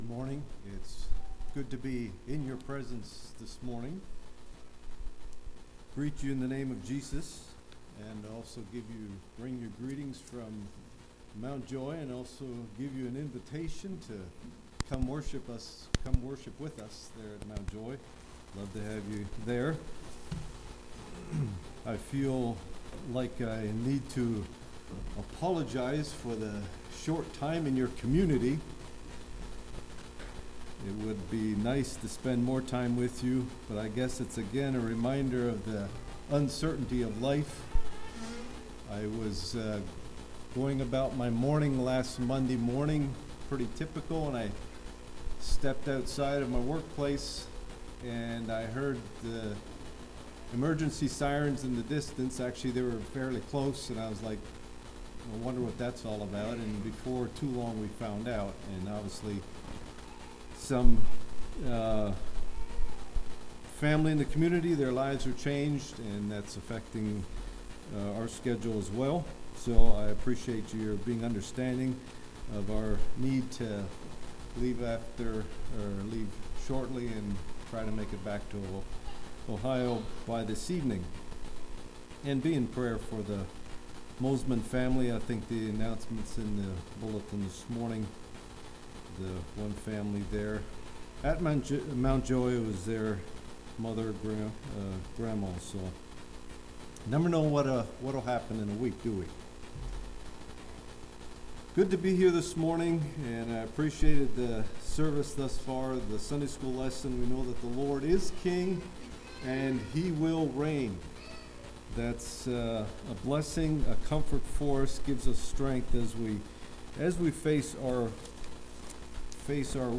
Play Now Download to Device Blessed Are They That Hunger Congregation: Great Lakes Speaker